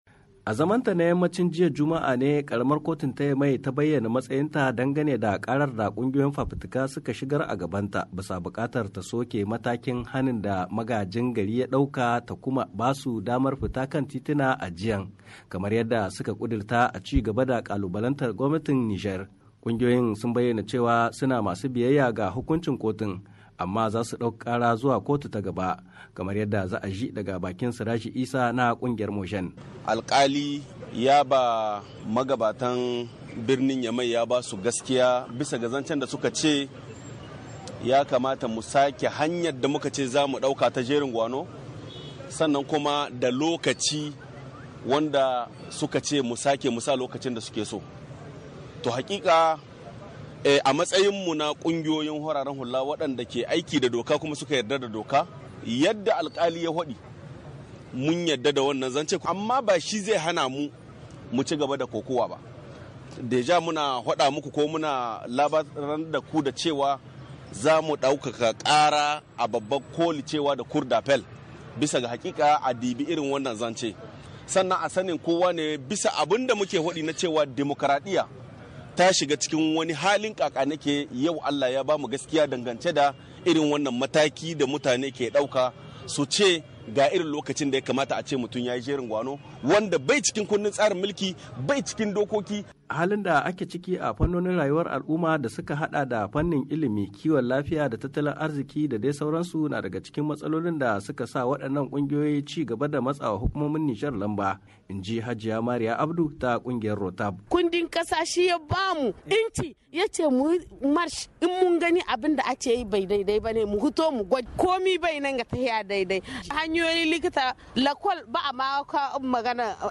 Rahoton Hana zanga zanga a Nijar-3:26"